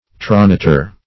Search Result for " tronator" : The Collaborative International Dictionary of English v.0.48: Tronator \Tro*na"tor\, n. [LL.